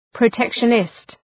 {prə’tekʃənıst}